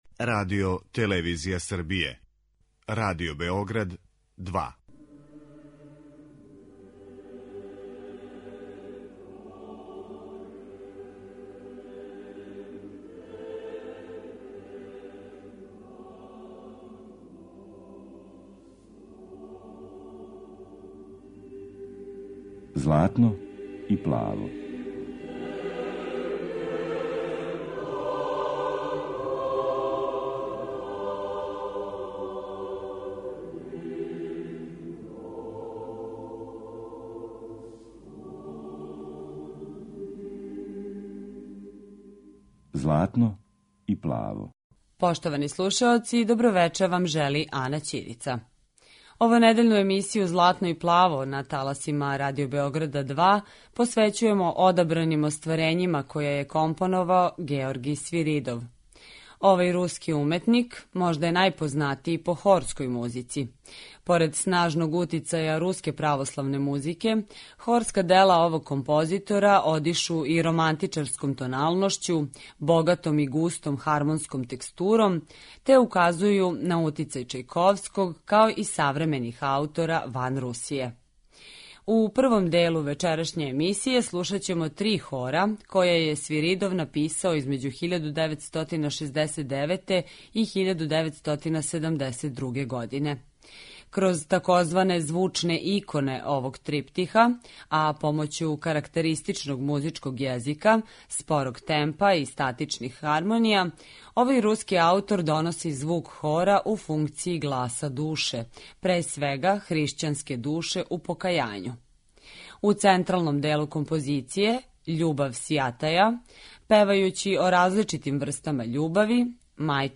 духовна дела